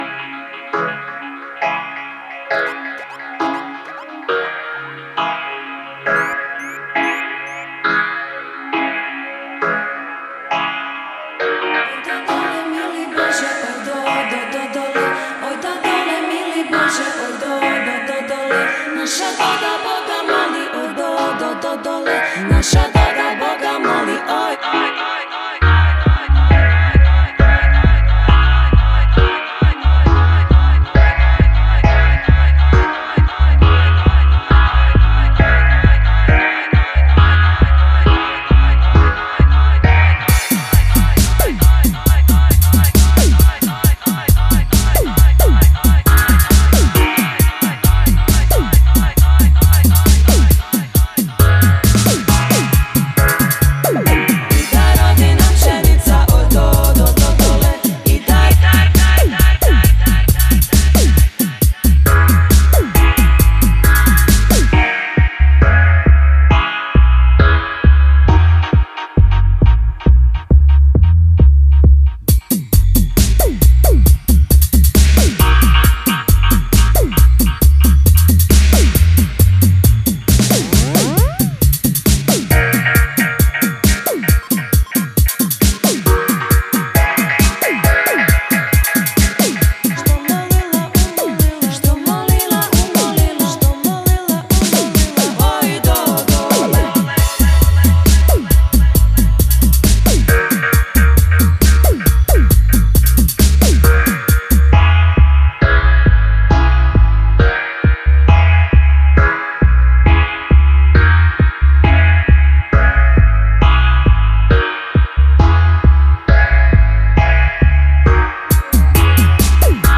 steepers & dub-roots-digital reggae